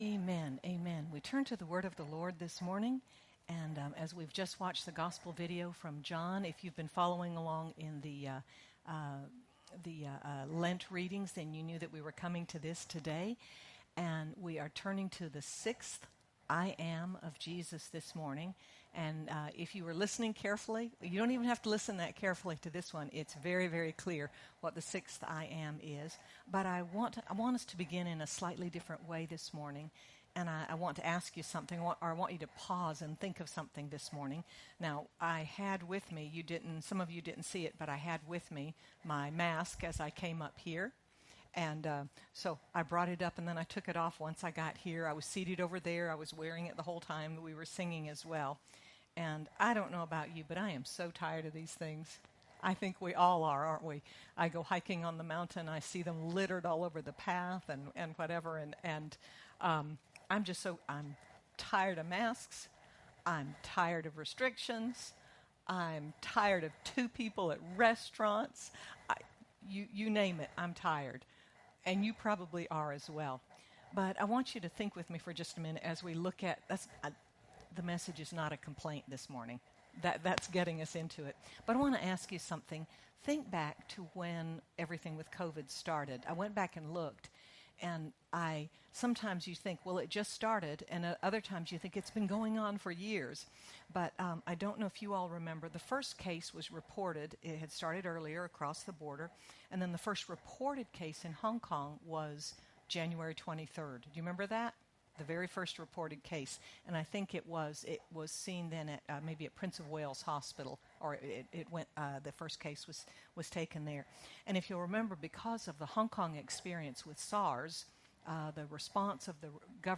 Jesus, the True Vine, offers a connection that gives abundant life and purpose—as you abide and remain in Him. Sermon by